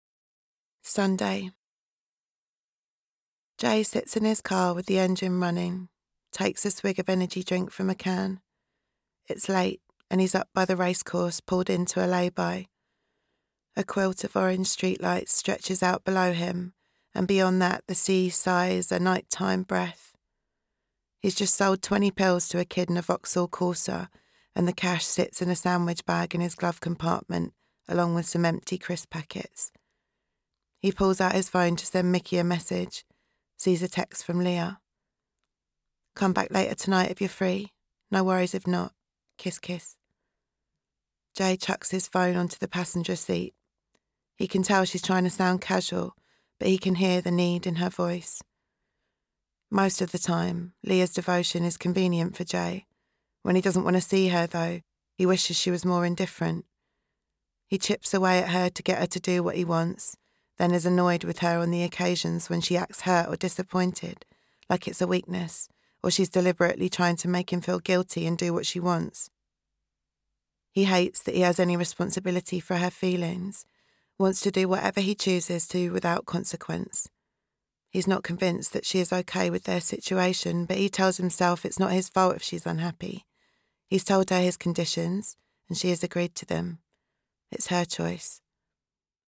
Little Boxes- Audiobook